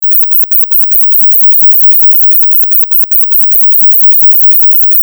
Звуки отпугивающие
В этой подборке собраны эффективные звуки для отпугивания нежелательных гостей: ультразвуковые сигналы, резкие шумы, природные звукоэффекты.
Звук, который отпугивает мышей и других грызунов